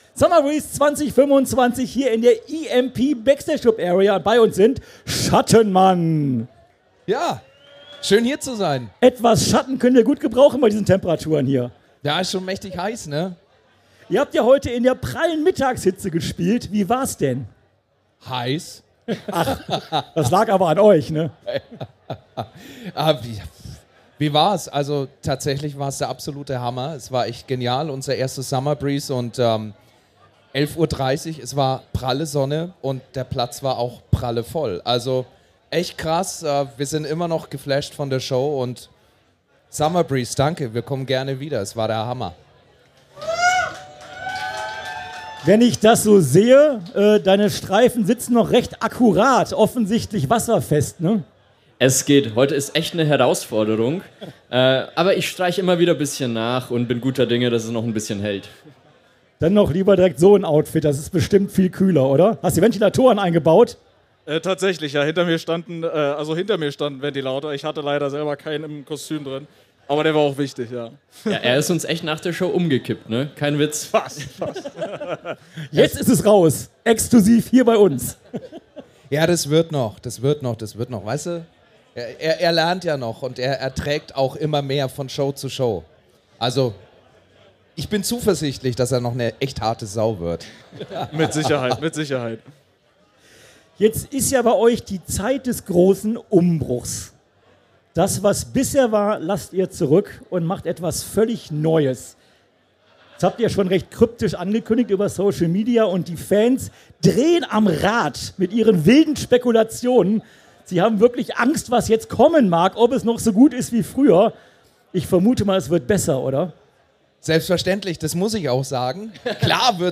Summer Breeze 2025 Special - Schattenmann - Live aus der EMP Backstage Club Area